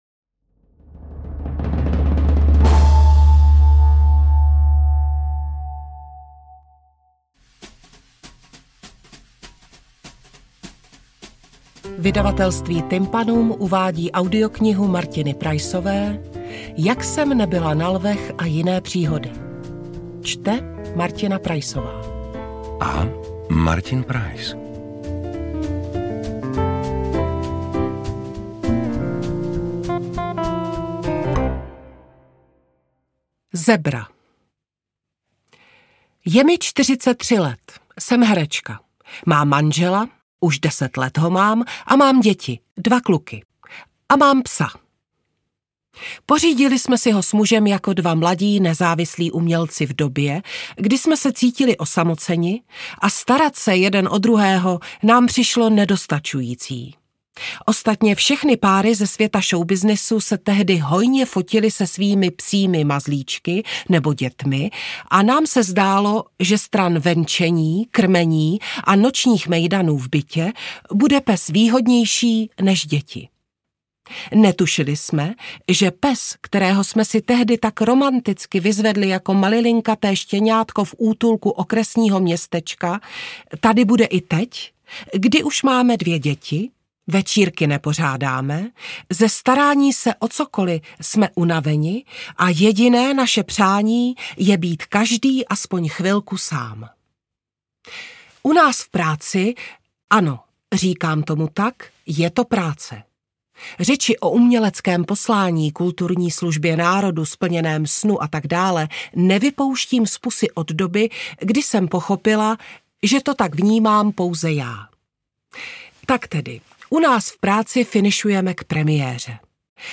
Interpreti:  Martin Preiss, Martina Preissová
AudioKniha ke stažení, 28 x mp3, délka 3 hod. 26 min., velikost 187,9 MB, česky